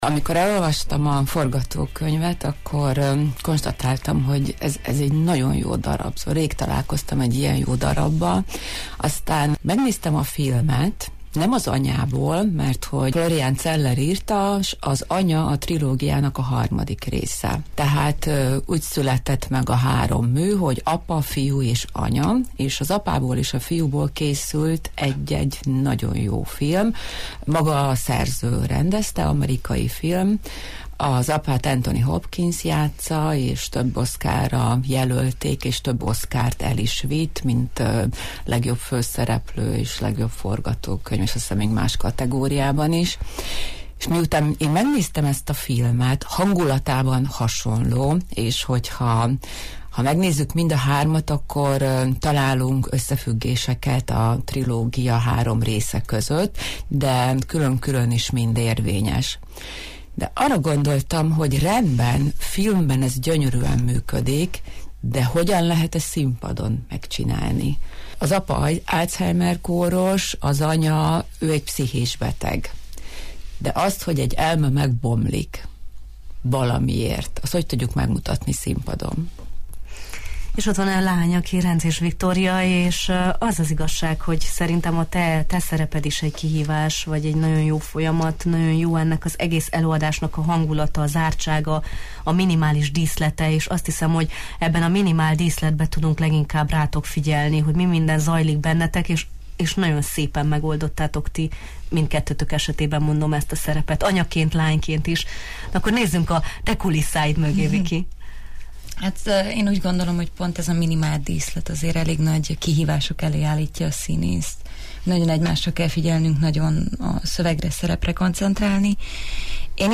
színművészek voltak a Jó reggelt, Erdély! vendégei: